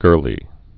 (gûrlē)